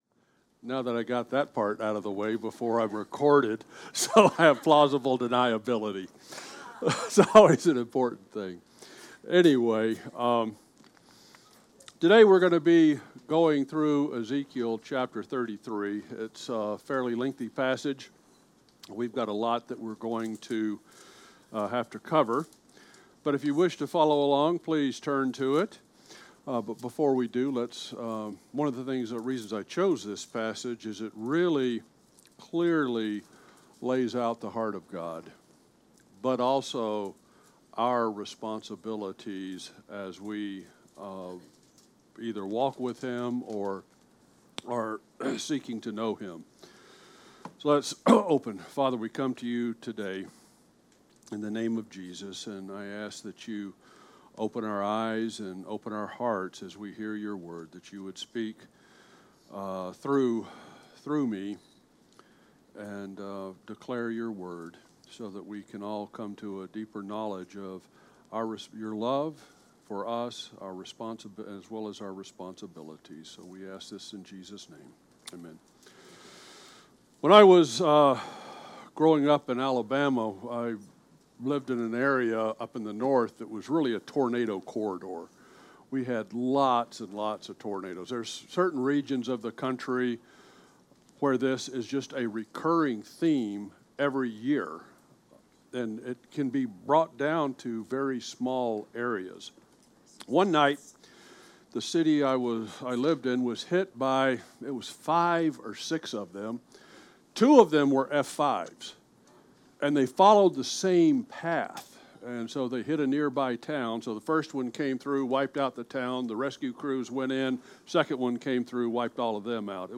at the pulpit going through Ezekiel 33